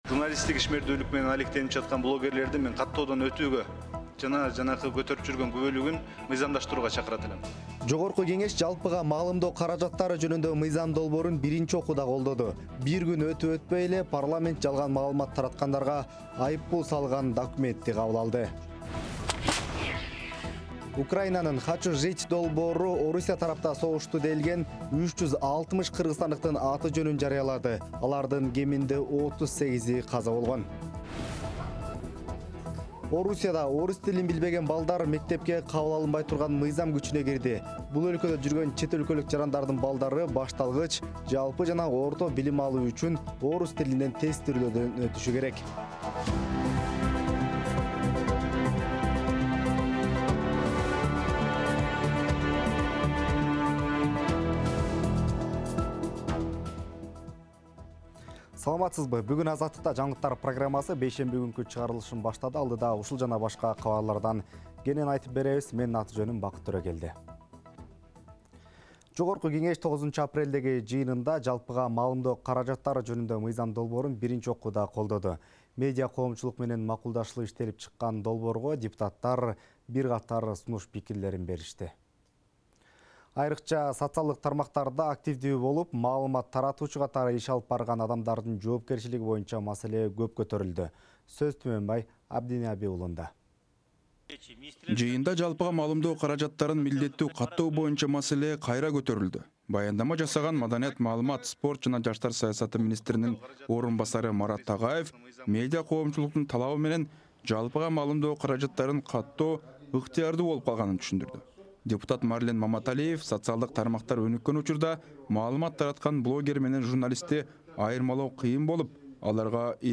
Жаңылыктар | 10.04.2025 | Орусияда тил билбеген балдар мектепте алынбайт